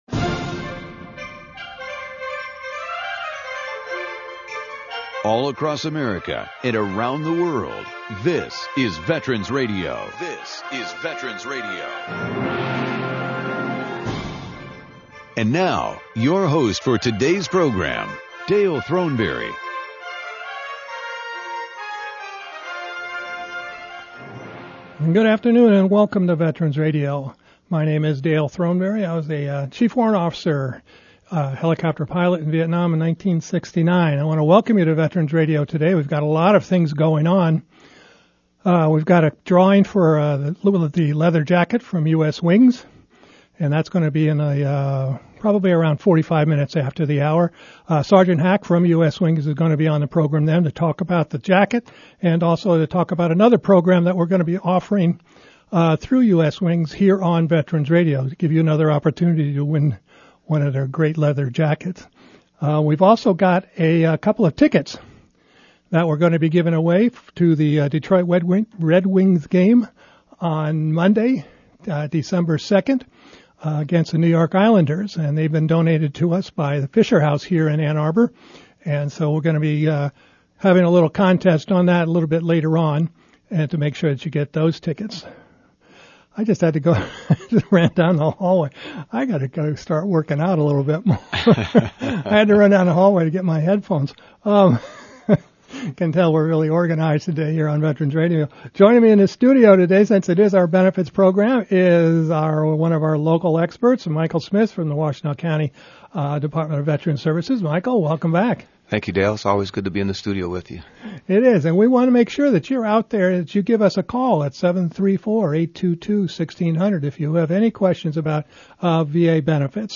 Listen in as our expert panel of VA service officers and providers discuss today’s healthcare and benefits earned by millions of veterans who have served.
VA Benefits Panel - November 2019
Call us with your questions during the live broadcast!